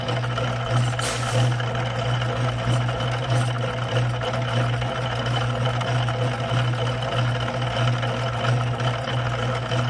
金属店" 机器 金属切割器 磨床 滚子 关闭1
Tag: 切割机 关闭 机器 金属 粉碎机